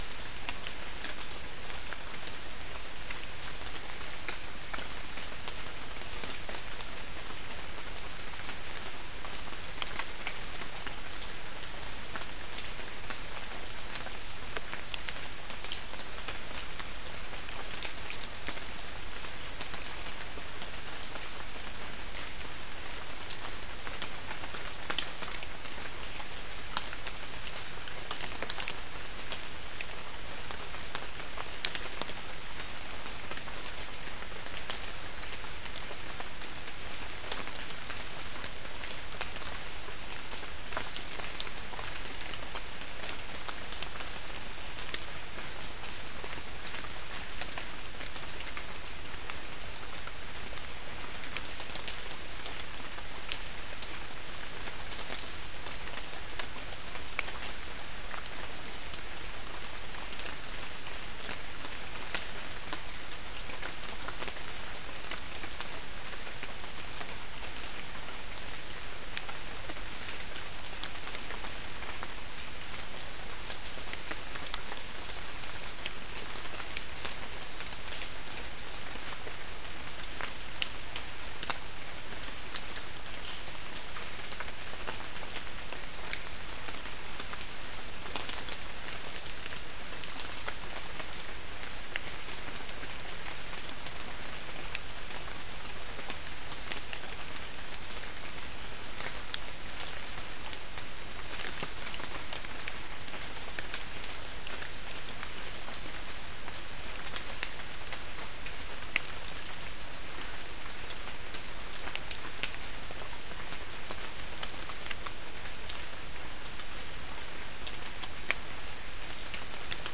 Soundscape
South America: Guyana: Rock Landing: 4
Recorder: SM3